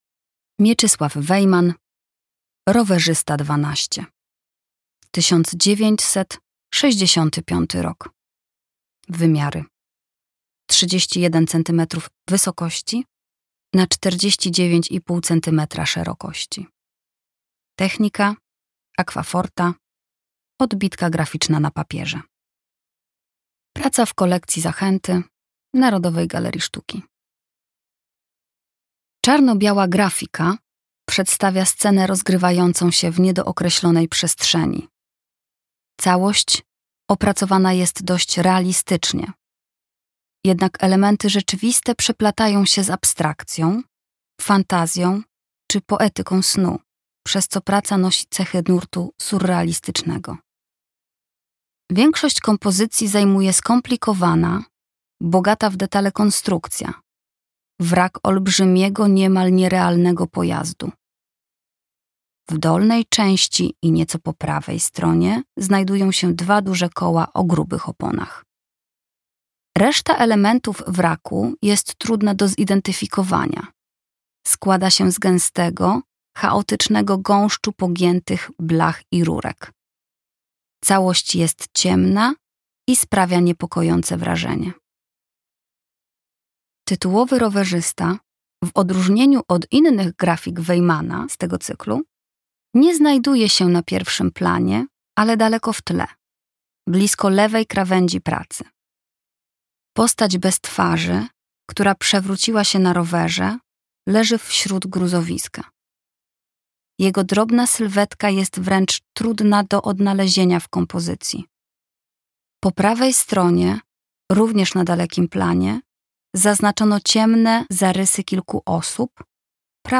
audiodeskrypcja